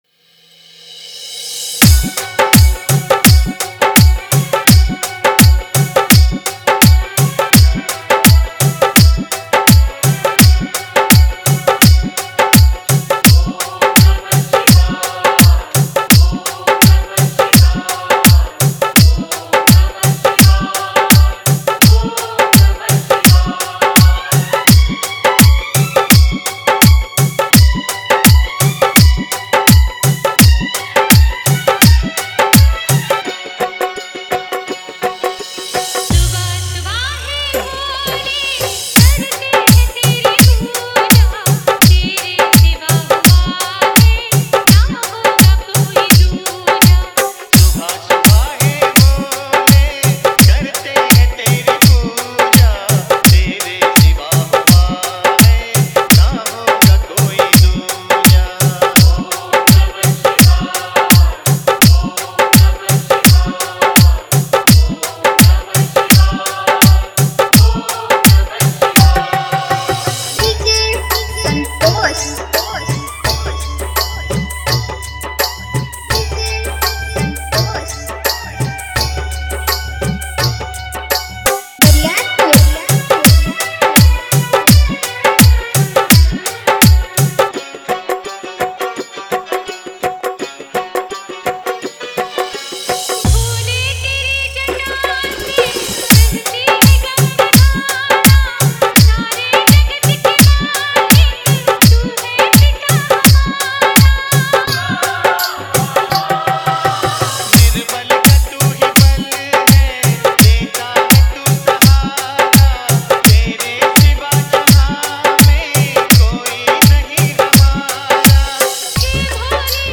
Bhakti Festival Remix Songs